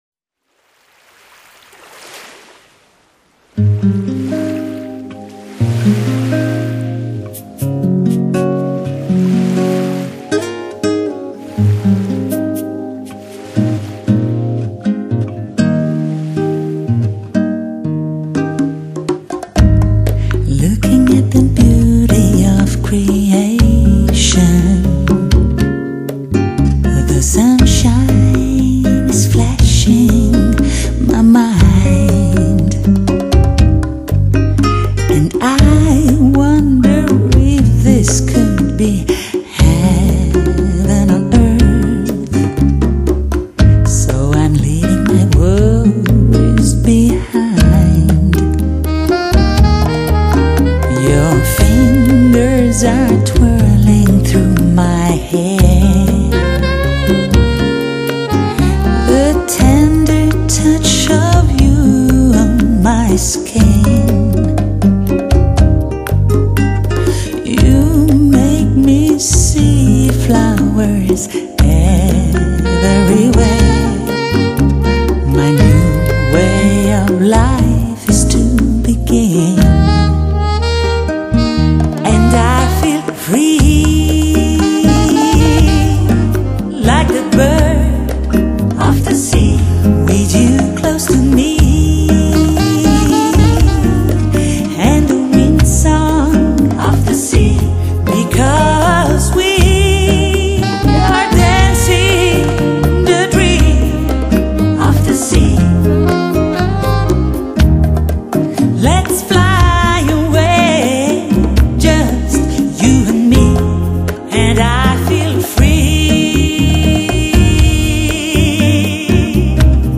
唱片风格：Chillout